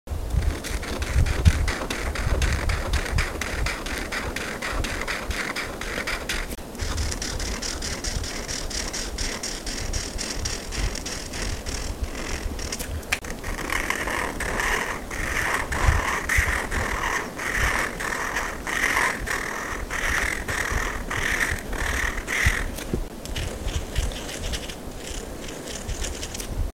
Stroller ASMR Sound Effects Free Download